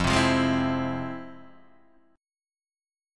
F Minor Major 7th